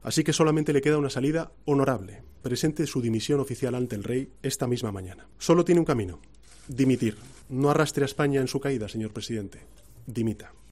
En una declaración leída en la sede madrileña del PSOE, Sánchez ha arremetido contra el jefe del Ejecutivo, al que ha acusado de ser el "principal responsable político" del "clima generalizado de corrupción" que se vive en España, con el PP implicado en numerosas investigaciones judiciales.